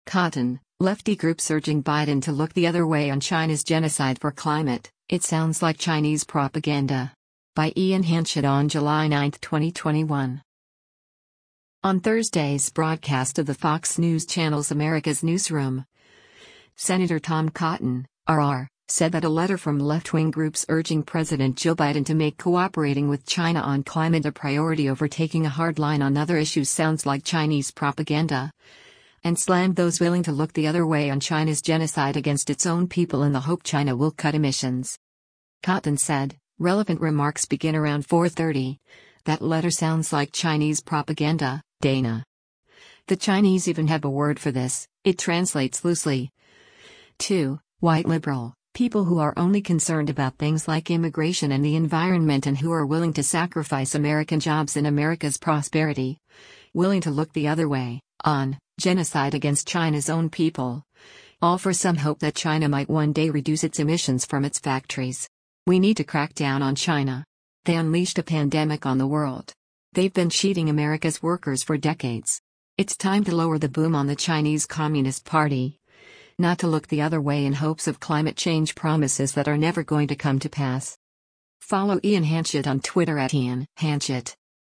On Thursday’s broadcast of the Fox News Channel’s “America’s Newsroom,” Sen. Tom Cotton (R-AR) said that a letter from left-wing groups urging President Joe Biden to make cooperating with China on climate a priority over taking a hard line on other issues “sounds like Chinese propaganda,” and slammed those “willing to look the other way” on China’s genocide against its own people in the hope China will cut emissions.